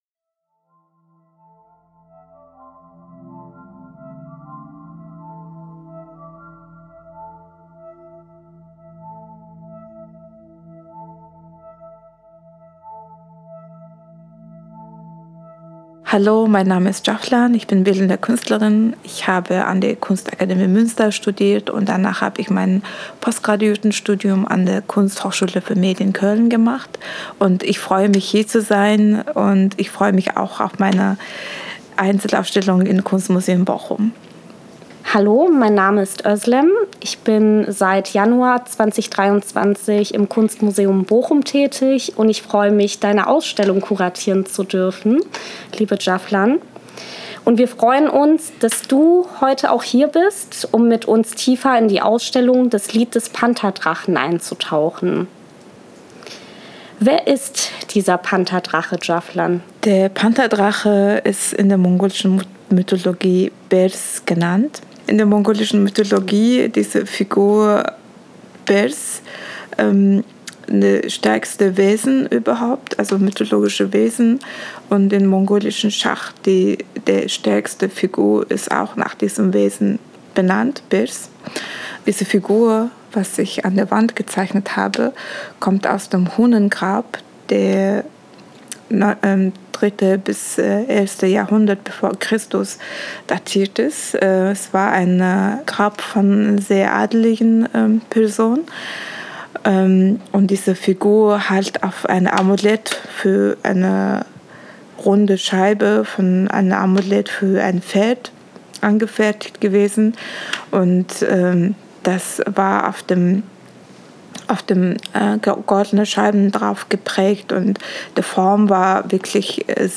Audiotalk – Kunstmuseum Bochum